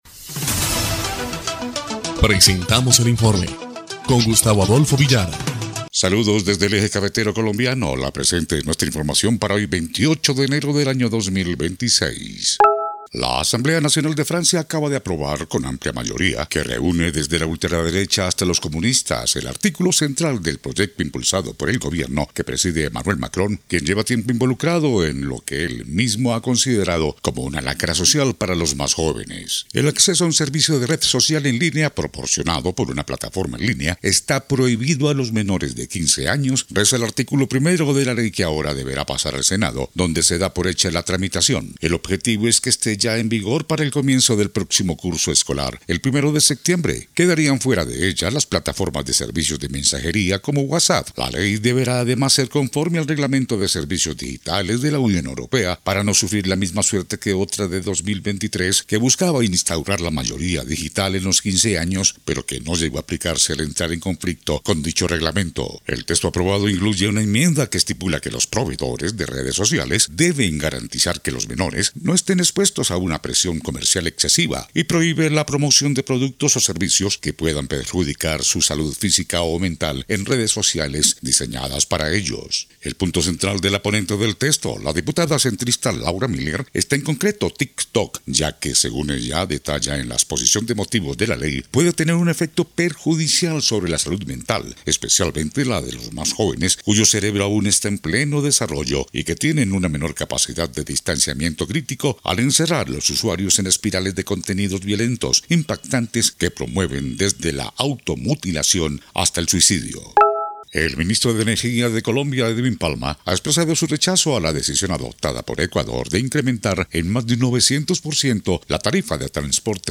EL INFORME 1° Clip de Noticias del 28 de enero de 2026